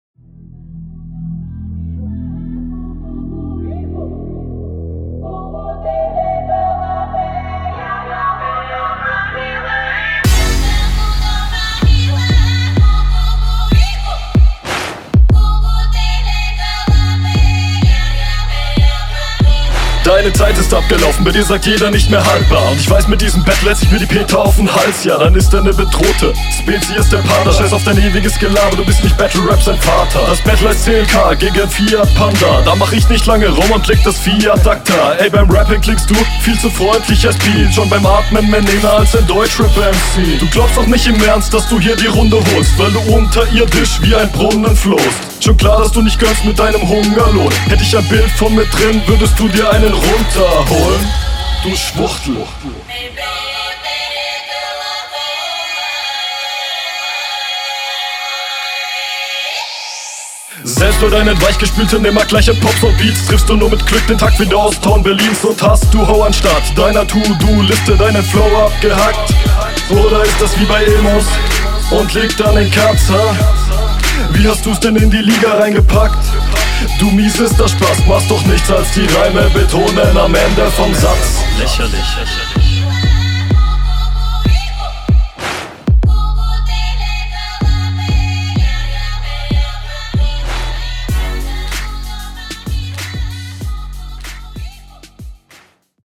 Der Einstieg ist tatsächlich ganz solide geflowt, im Laufe der Runde verfällst du aber in …
Sound und Flow: Mix ist deutlich schlechter als in RR1.